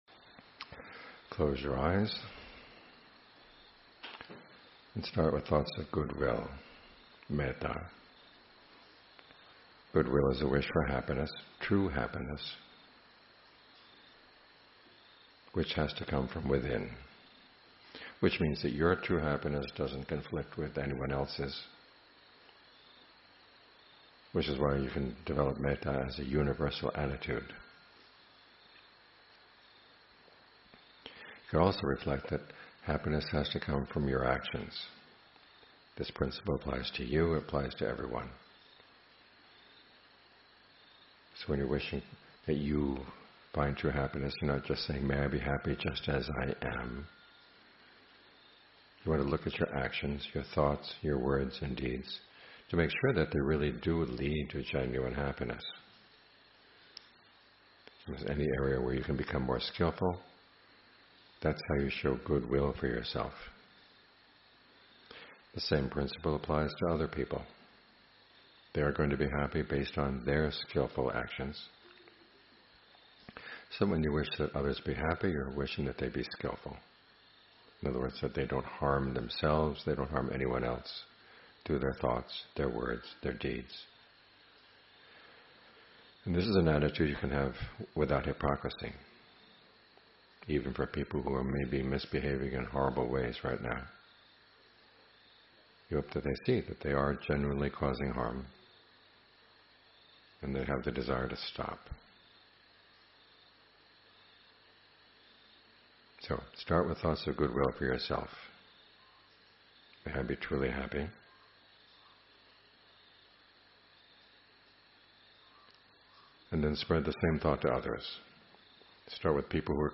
Guided Meditations
The audio has been edited to remove unnecessary parts and some of the ending bells have been removed to allow for one to continue without a time limit.